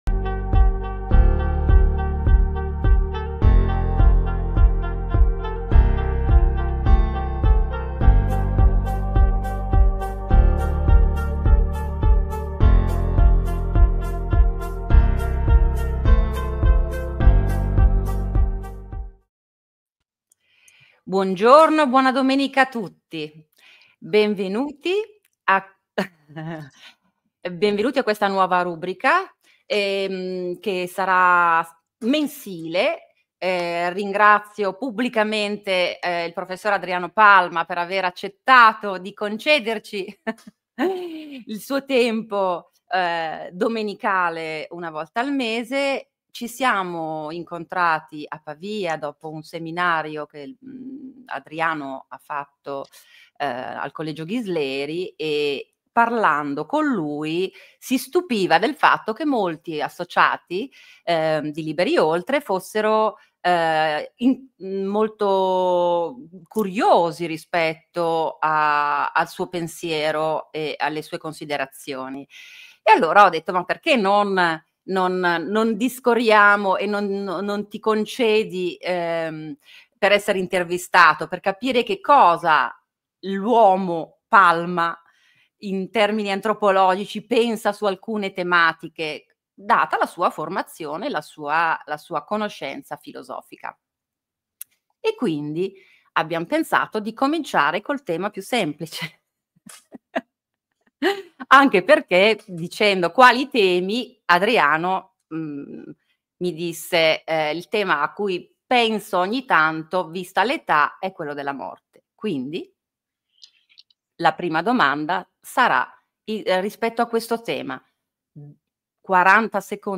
In onda in diretta la prima Domenica di ogni mese. Un appuntamento mensile con la filosofia e il pensiero umano su Liberi Oltre Le Illusioni - Agorà